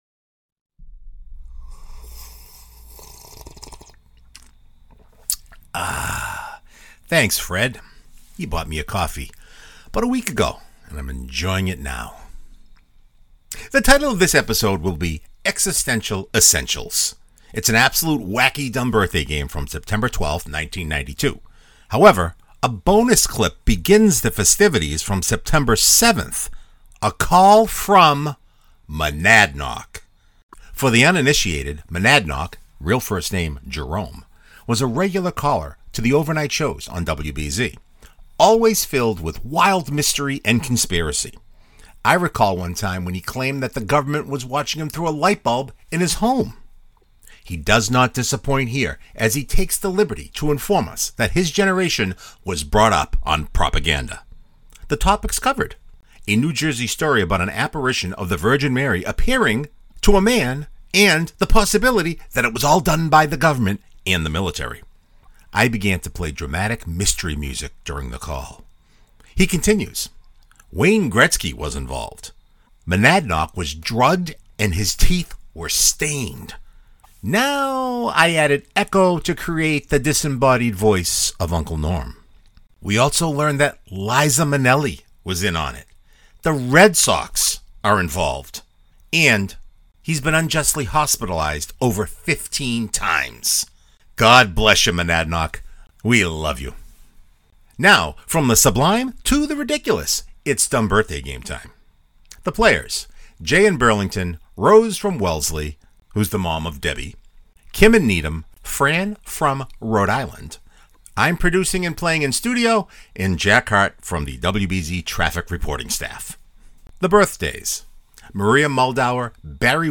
Topics covered: A New Jersey story about an apparition of the Virgin Mary appearing to a man and the possibility that it was all done by the government and military. I began to play the dramatic, mystery music during the ca